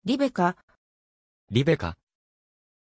Pronunția numelui Rebekkah
pronunciation-rebekkah-ja.mp3